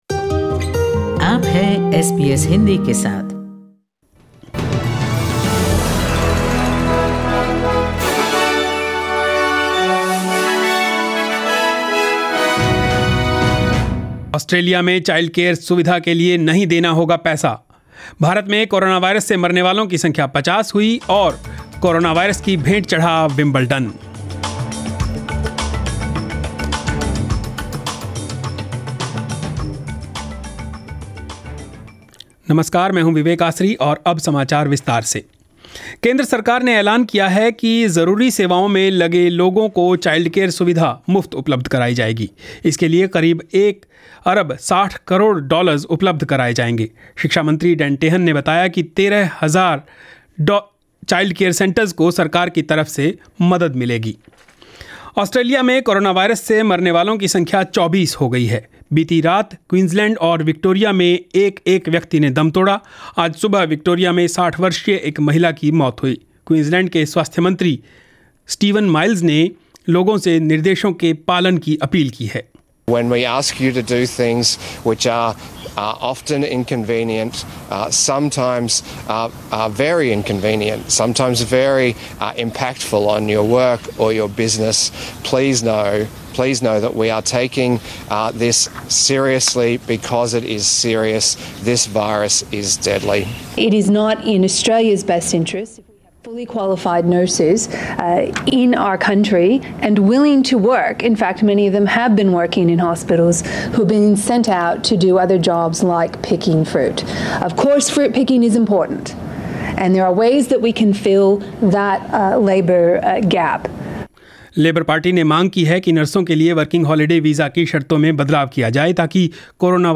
News in Hindi 2 April 2020